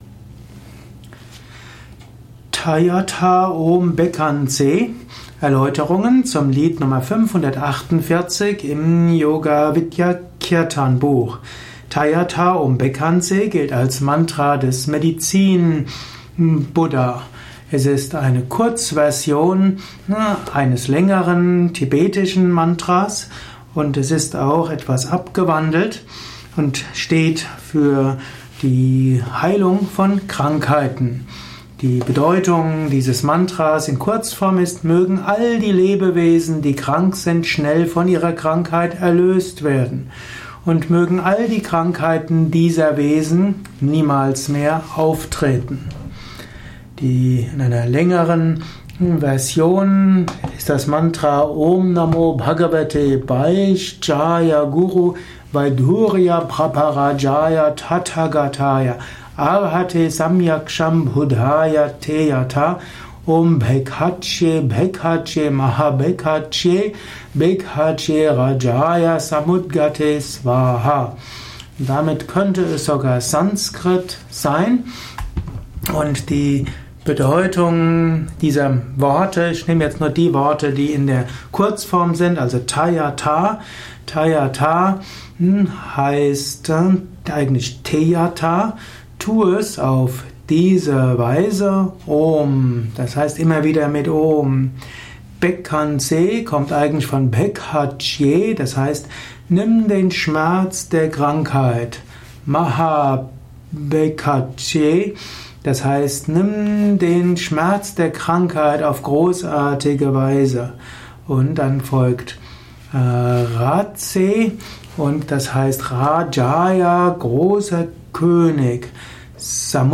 Kirtanheft , Tonspur eines Kirtan Lehrvideos.